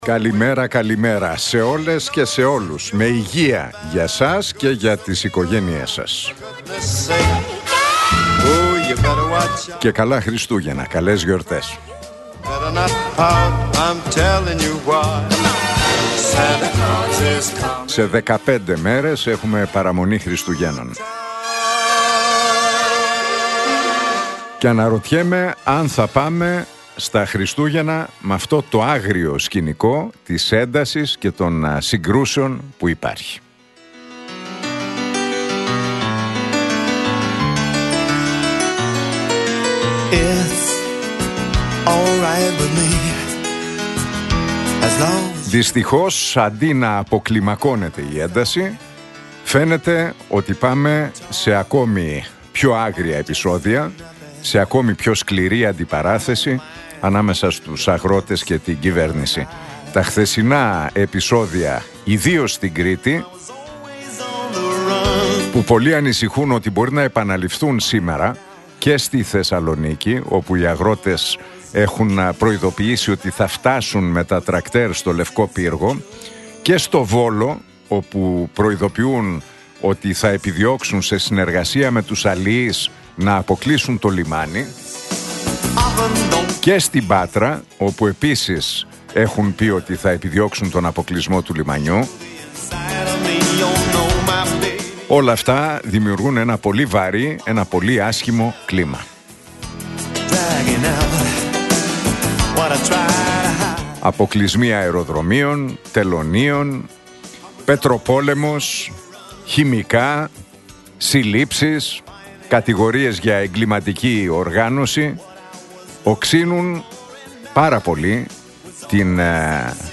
Ακούστε το σχόλιο του Νίκου Χατζηνικολάου στον ραδιοφωνικό σταθμό Realfm 97,8, την Τρίτη 9 Δεκεμβρίου 2025.